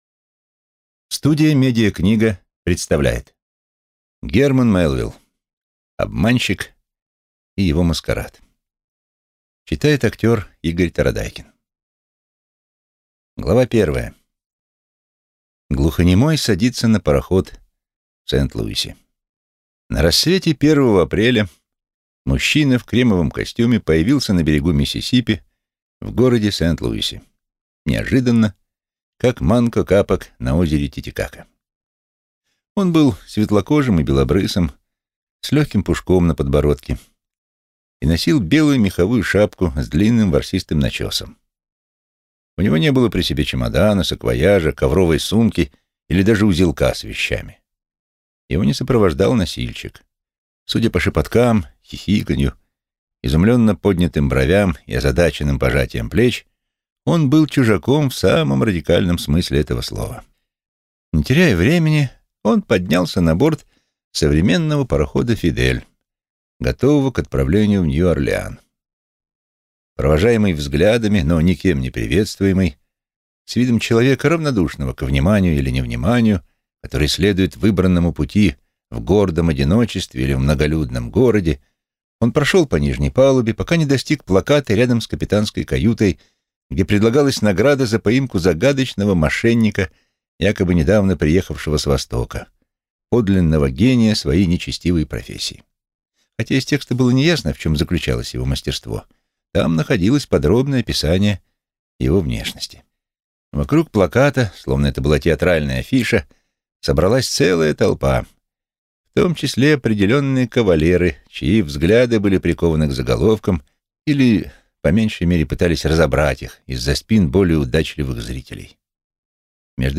Аудиокнига Обманщик и его маскарад | Библиотека аудиокниг